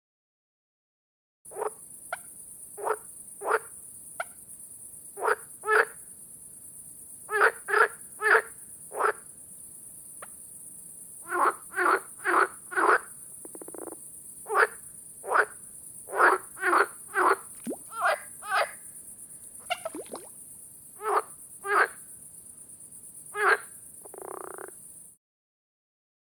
shinny frog coaching
Category 🌿 Nature
ambiance field-recording frog frogs insects nature night summer sound effect free sound royalty free Nature